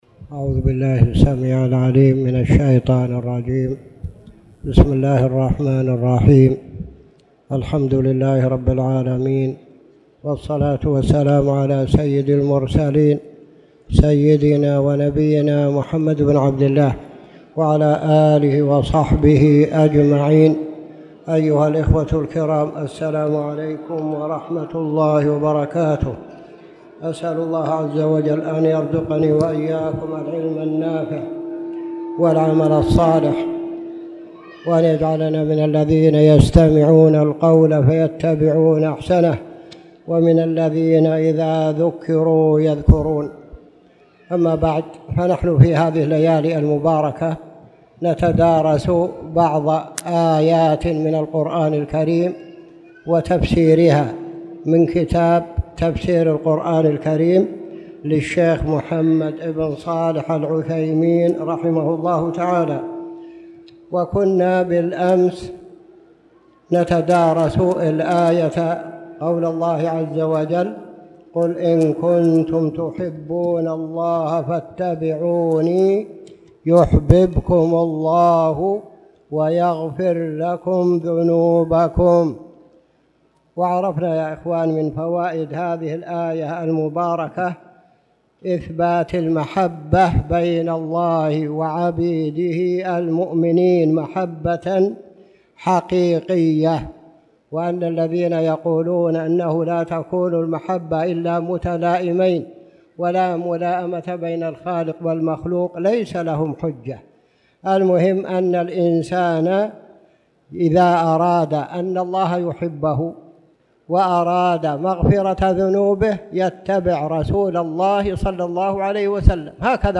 تاريخ النشر ٨ جمادى الأولى ١٤٣٨ هـ المكان: المسجد الحرام الشيخ